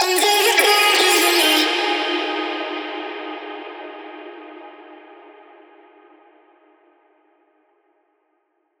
VR_vox_hit_intothedark_D#.wav